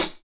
HAT KLB CH0B.wav